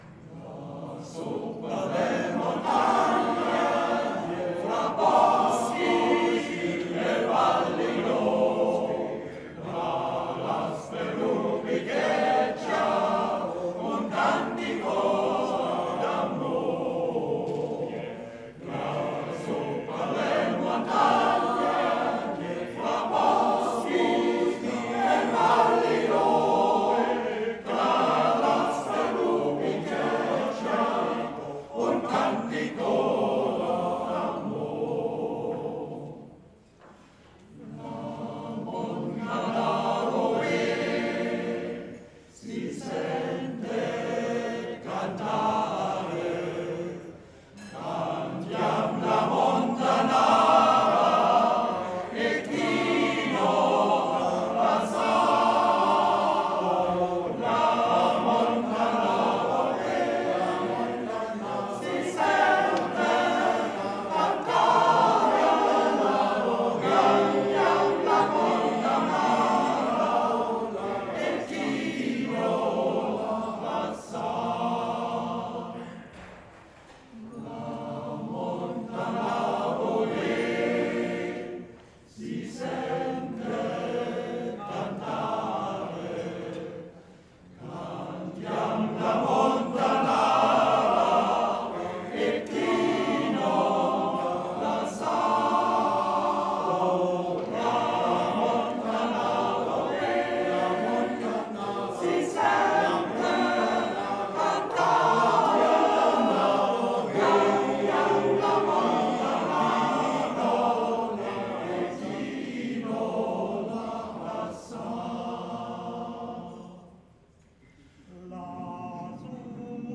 Sängerkranz Alfdorf – La Montanara (21.01.2017 Jahresfeier)